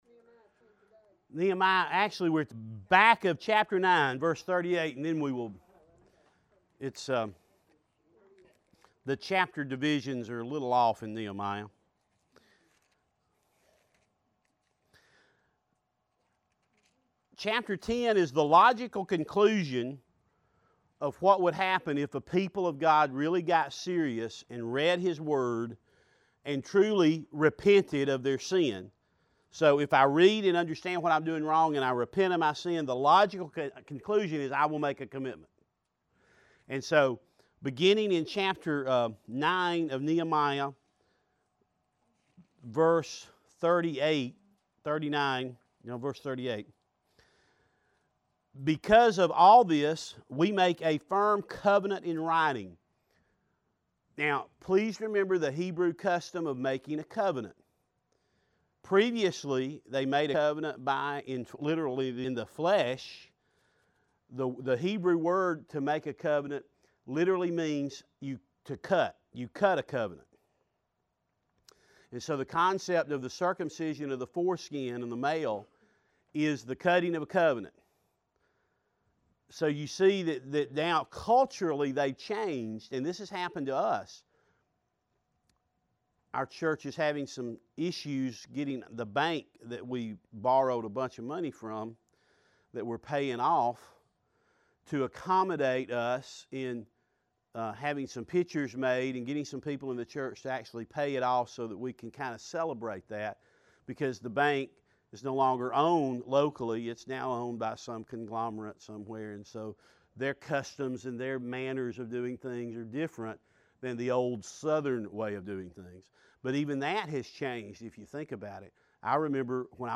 teaches a spring semester class